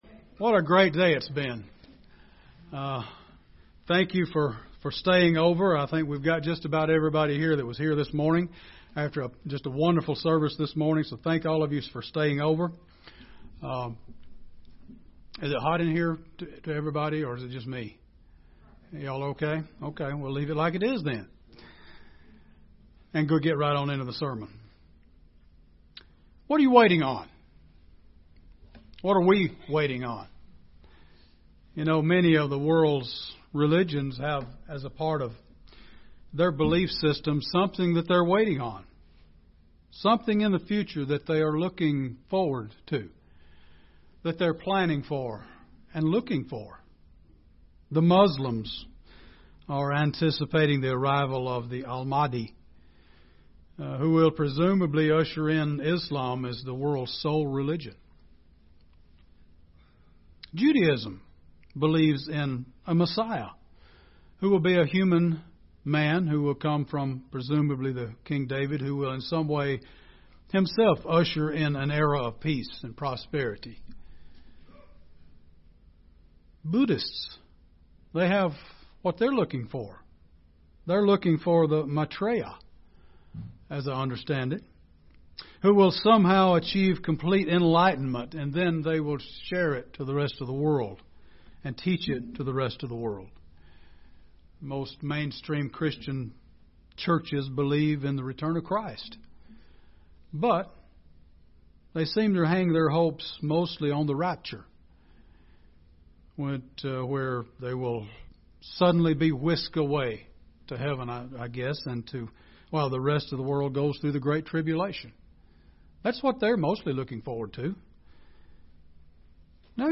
Given in Gadsden, AL
UCG Sermon Studying the bible?